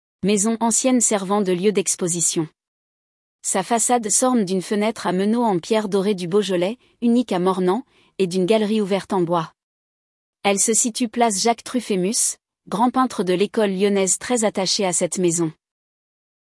audio guide de la maison de Pays